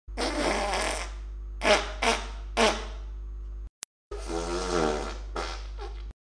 1 Bruit De Pet